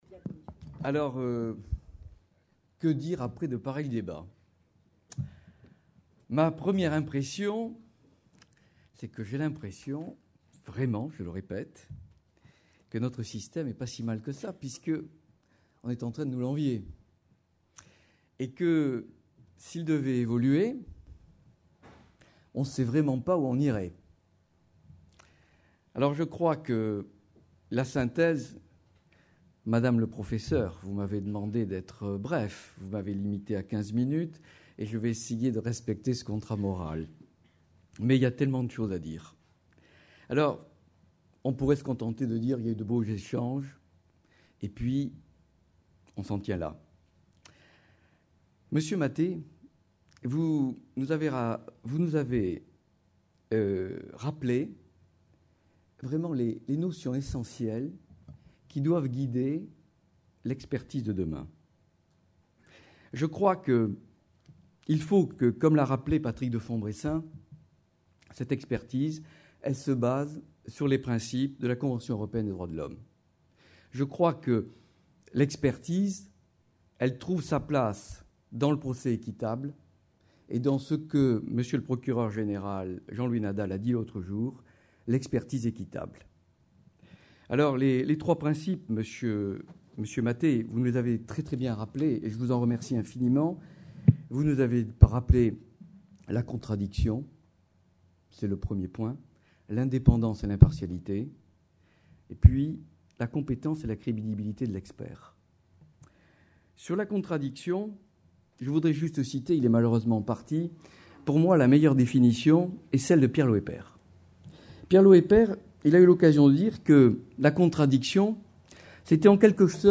Colloque des Compagnies des Experts de Justice du Grand Est. Organisé par la Compagnie de Reims sous la présidence d’honneur de Monsieur le Premier Président et de Monsieur le Procureur Général de la Cour d’Appel de Reims.